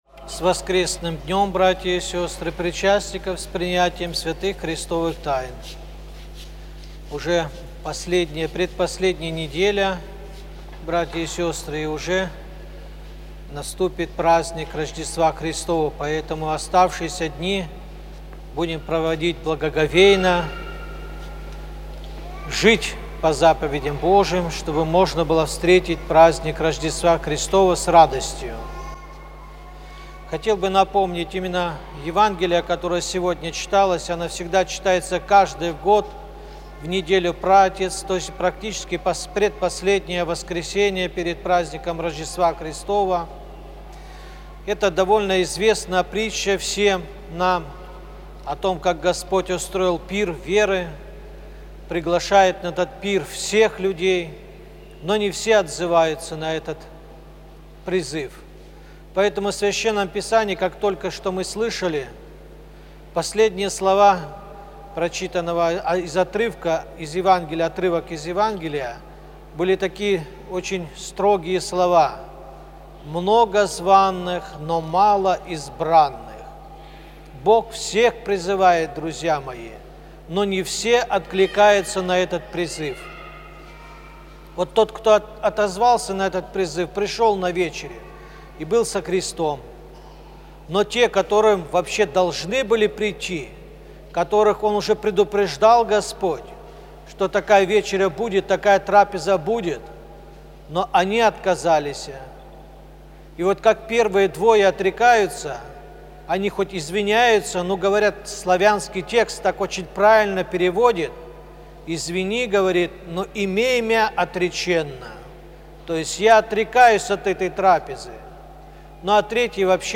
Проповедь на литургии читает епископ Балтийский Серафим